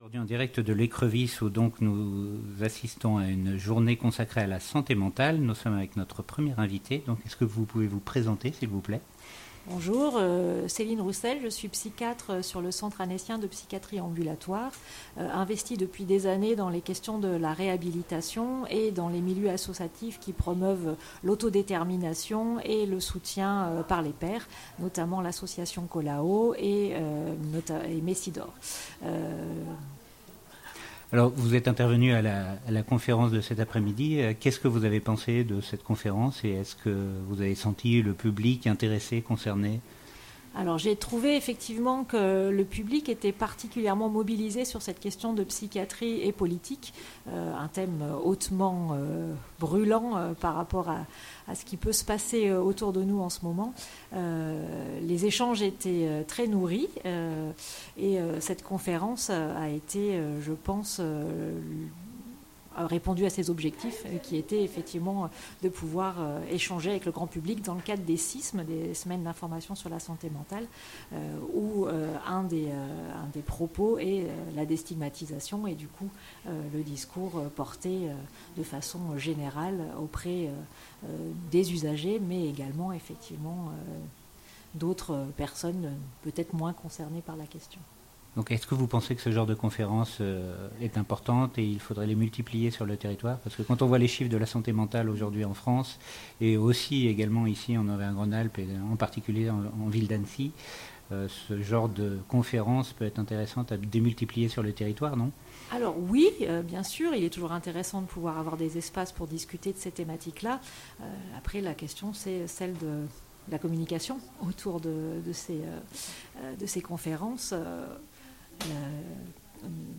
Interview des intervenants à la journée Santé mentale à l’Écrevis